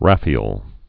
(răfē-əl, rāfē-, räfē-ĕl)